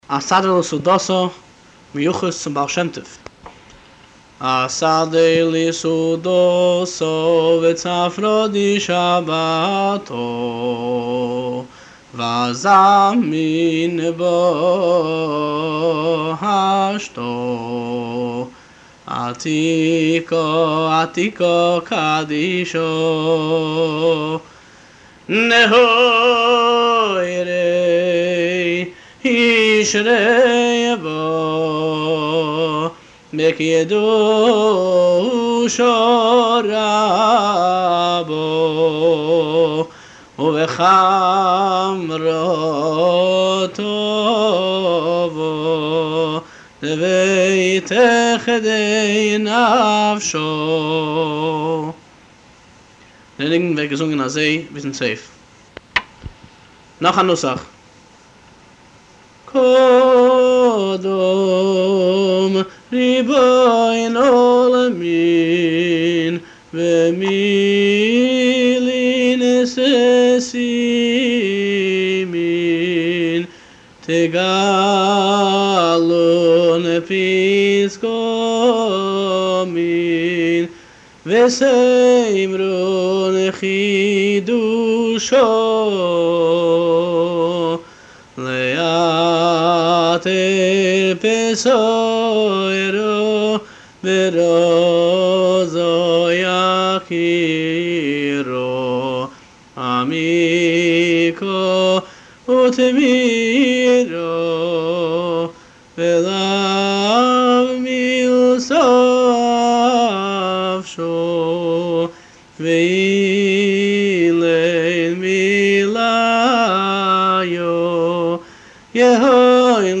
אסדר לסעודתא - ניגון המיוחס לבעל שם טוב הוא ניגון על הפיוט אסדר לסעודתא המקובל בפי חסידים כניגון שמקורו מ הבעל שם טוב .